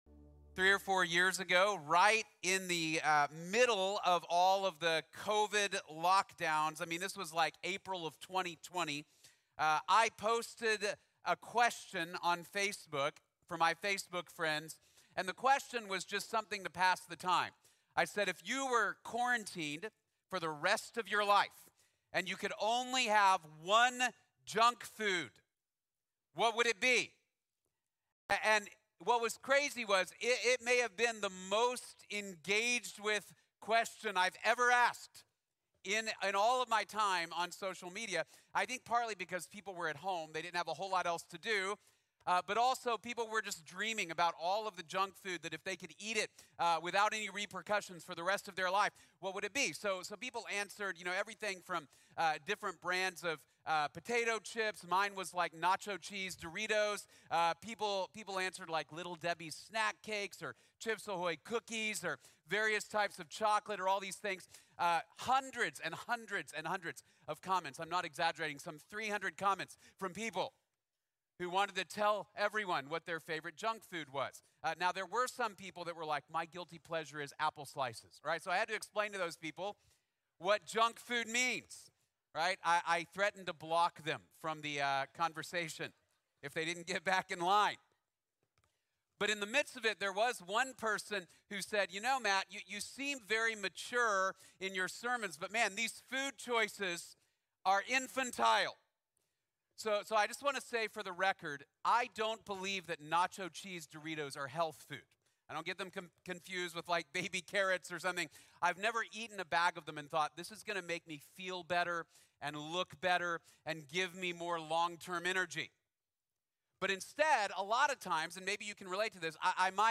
The Struggle is Real | Sermon | Grace Bible Church